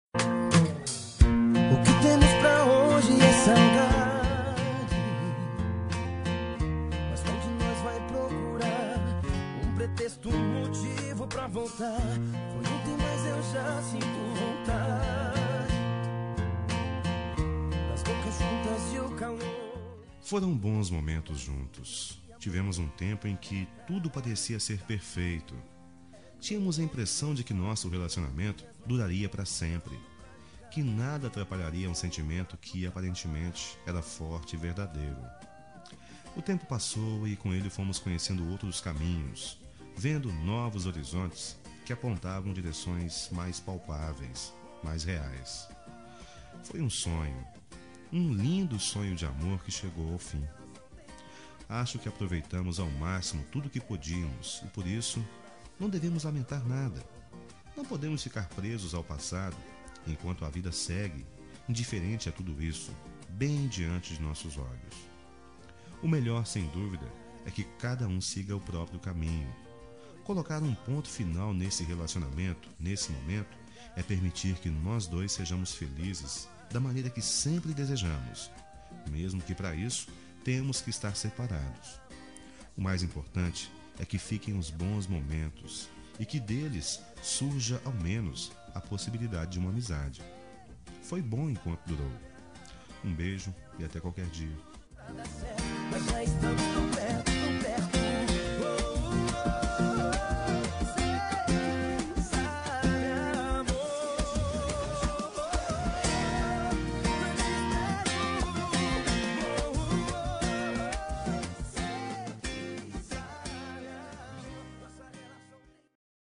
Término – Voz Masculina – Código: 8666